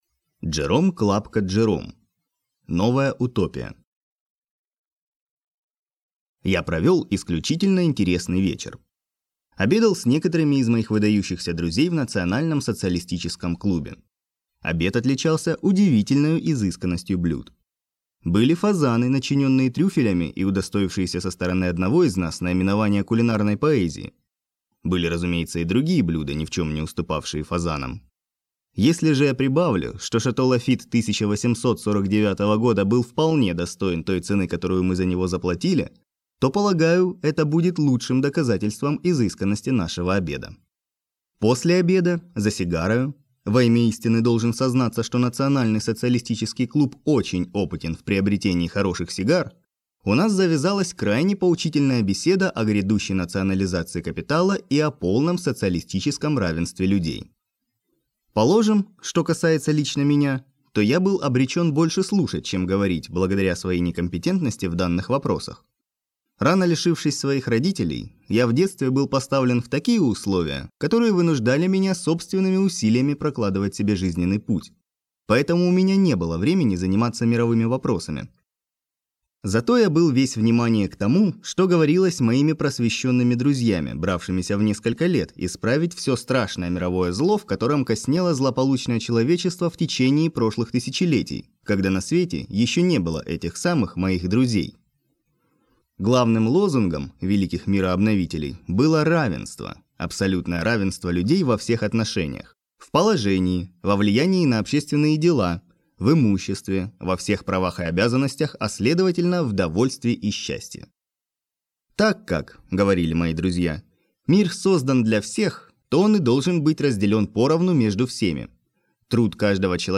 Аудиокнига Новая утопия | Библиотека аудиокниг